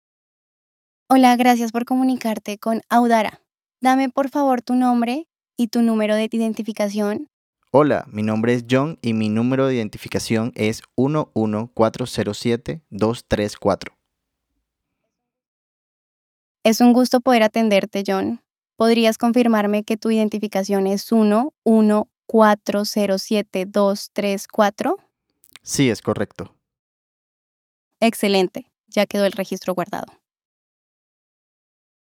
A continuación, podrás escuchar ejemplos reales de interacción con nuestros voicebots, en audios donde las voces responden y conversan en tiempo real.
➡ Ejemplo Voicebot: Interacción de un cliente con un Voicebot con acento colombiano